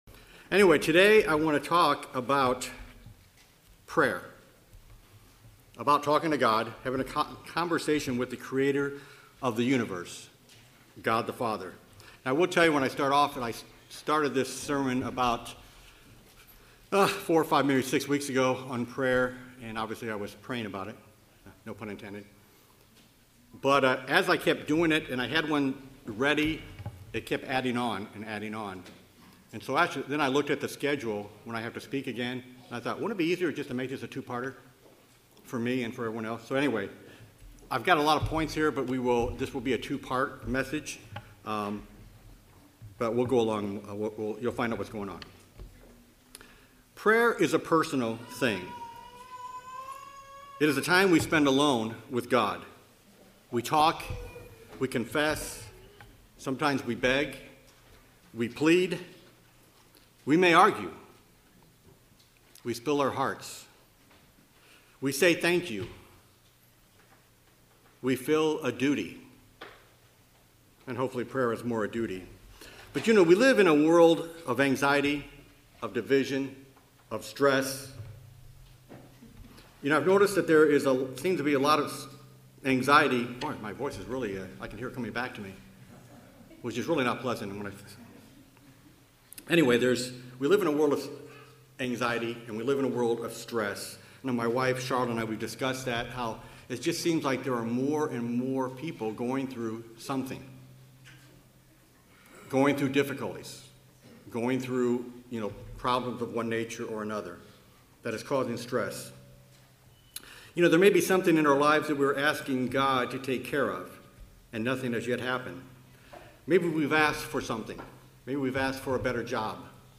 This sermon discusses some of those elements. But does God always hear us?